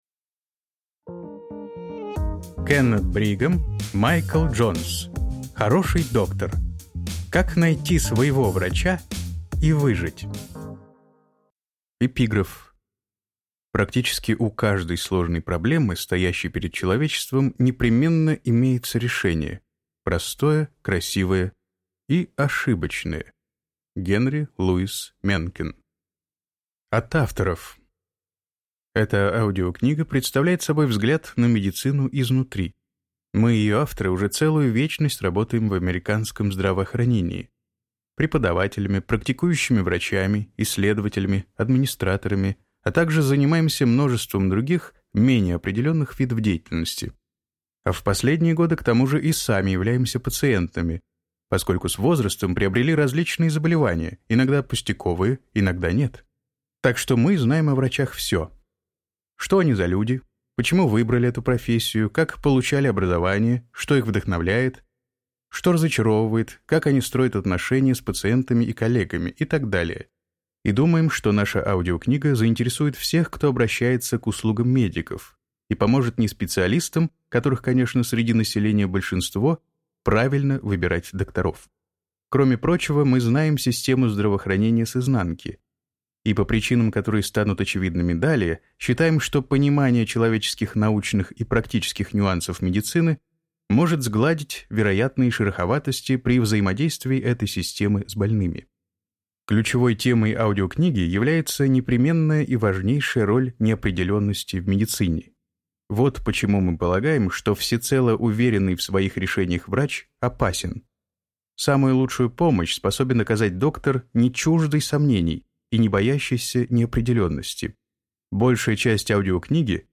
Аудиокнига Хороший доктор. Как найти своего врача и выжить | Библиотека аудиокниг